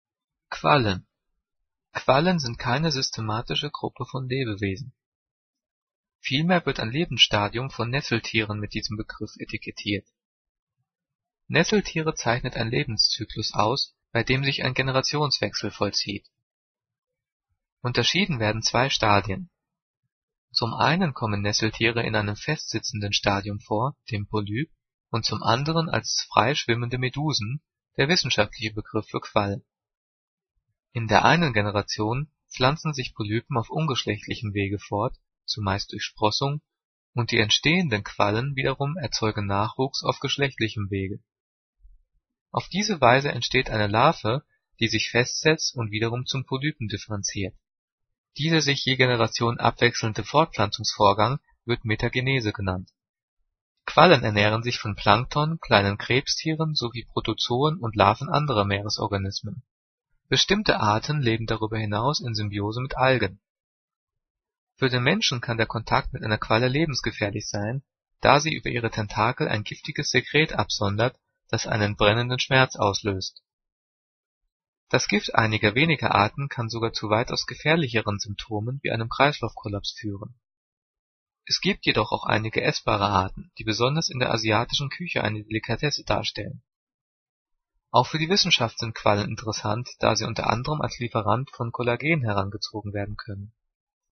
Diktat: "Quallen" - 9./10. Klasse - Fremdwörter
Gelesen:
gelesen-quallen.mp3